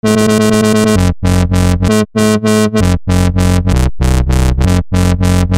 描述：摇摆不定的贝斯节奏130，希望你能使用它，可用于Dnb或Dubstep。
Tag: 130 bpm Drum And Bass Loops Bass Loops 956.74 KB wav Key : Unknown